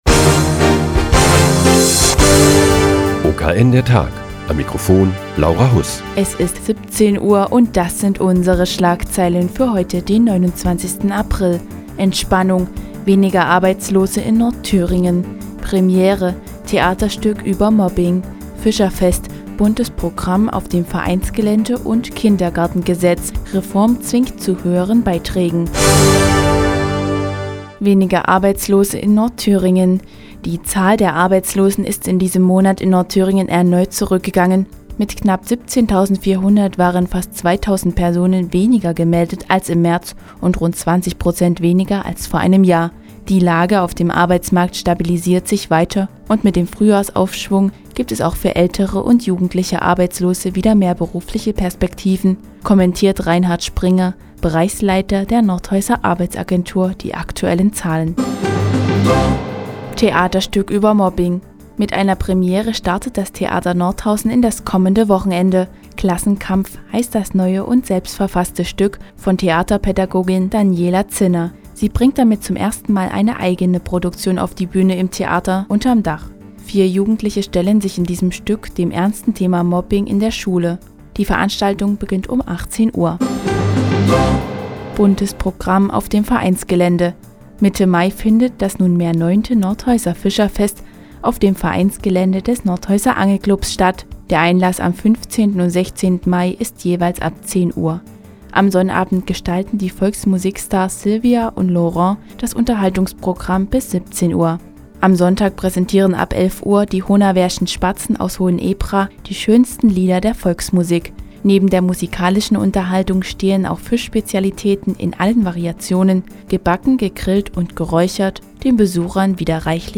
Die tägliche Nachrichtensendung des OKN ist nun auch in der nnz zu hören. Heute geht es um das neue Nordhäuser Theaterstück "Klassenkampf", das sich mit dem Thema Mobbing in der Schule beschäftigt und um die Kritik am Haushaltsentwurf der Landesregierung.